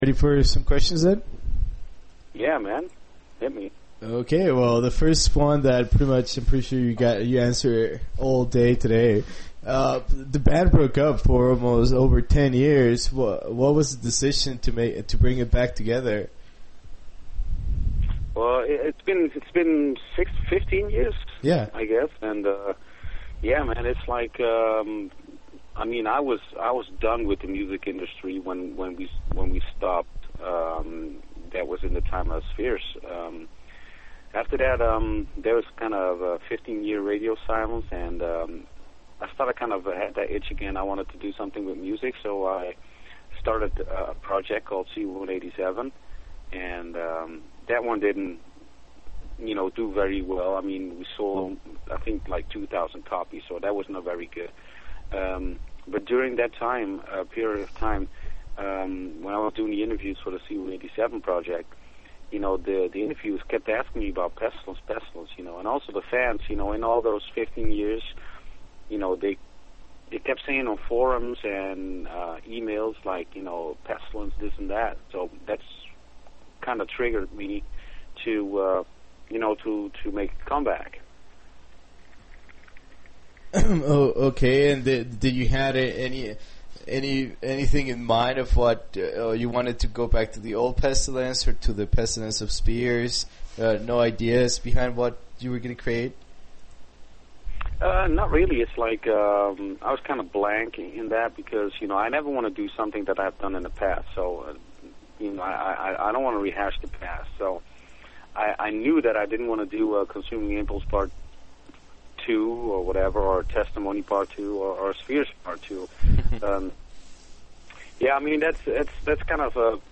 Interview with Pestilence - Patrick Mameli
To hear our 23 minute conversation in stereo with crisp sound and on MP3 format, click HERE or select Save As and take it with you.